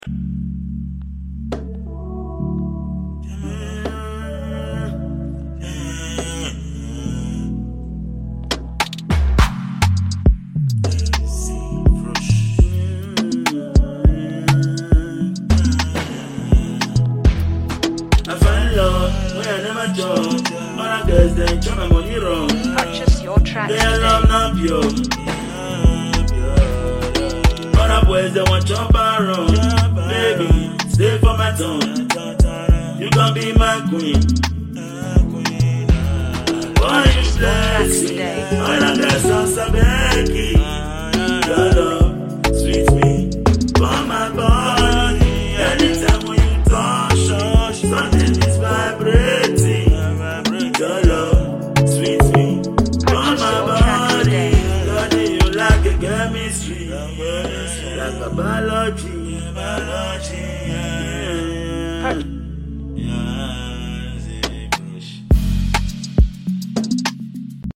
With its infectious energy and motivational message,